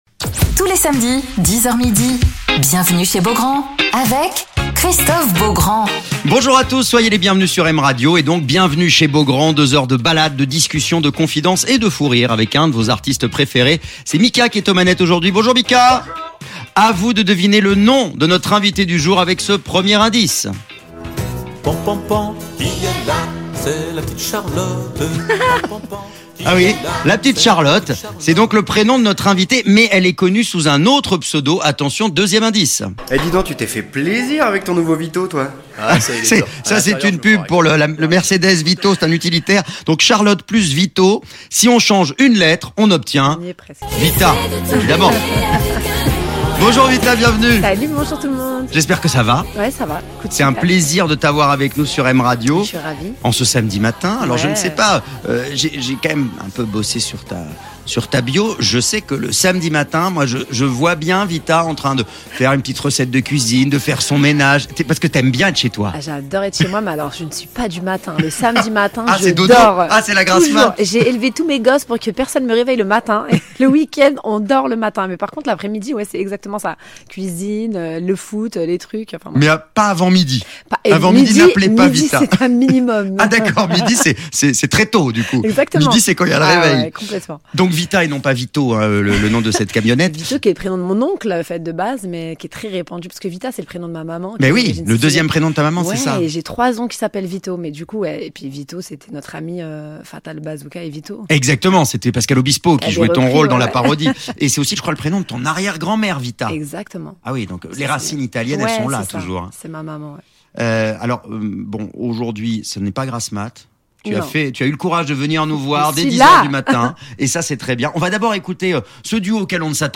Alors qu'elle prépare son retour sur scène cet été, Vitaa est l'invitée de Christophe Beaugrand sur M Radio